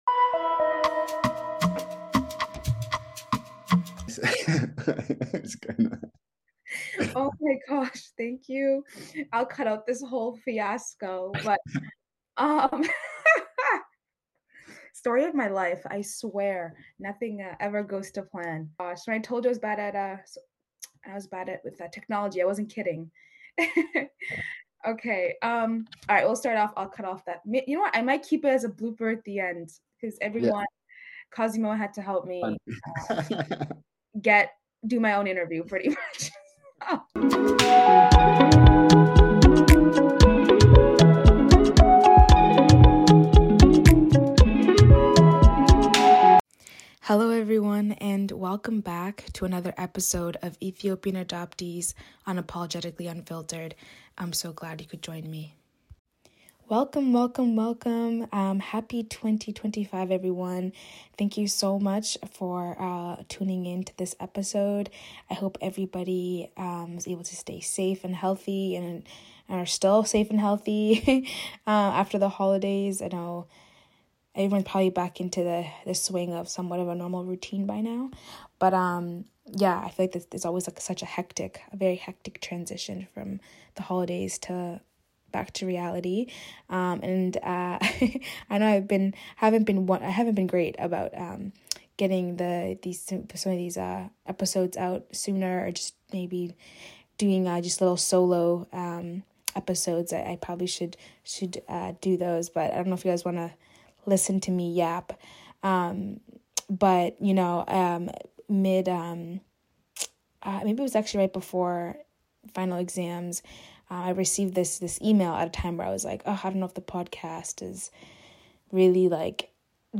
This conversation was raw, emotional, and deeply eye-opening.